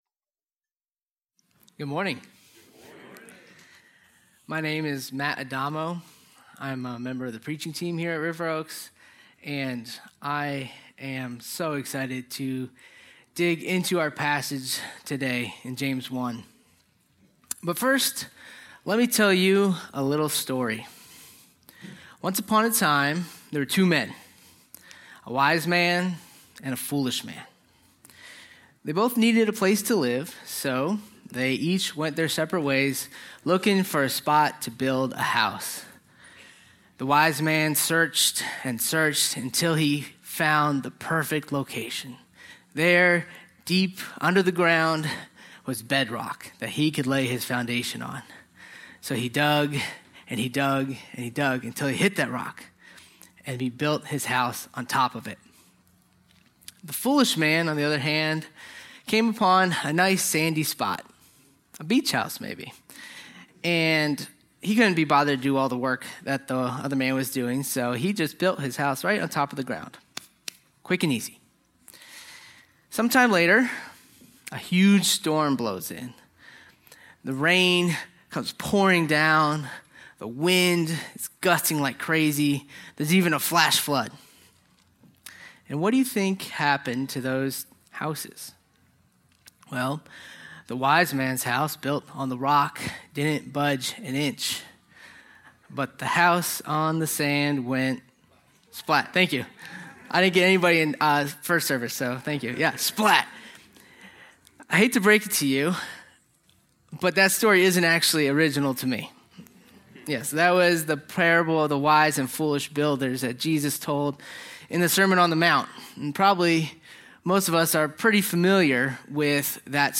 A sermon on James 1:22-25